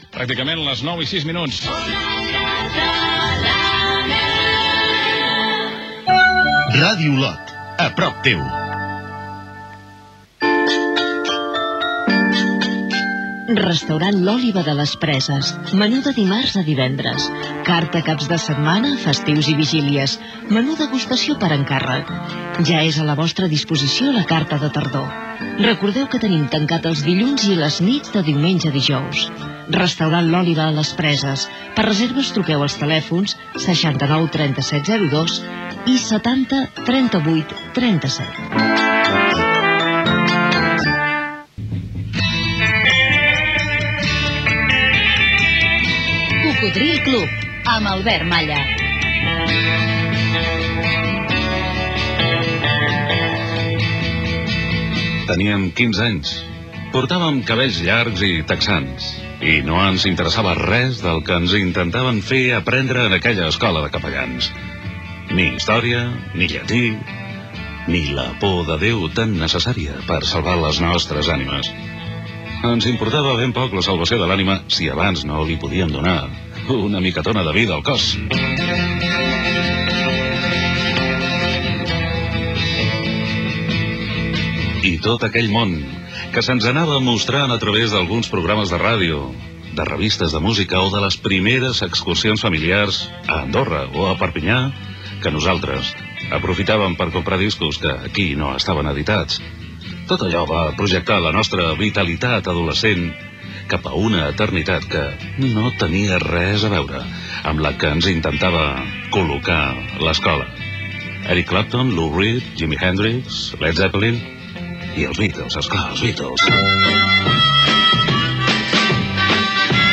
Hora, indicatiu Ona Catalana, indicatiu Ràdio Olot, publicitat i començament del programa "Cocodril Club" amb la careta i la presentació.
Musical
FM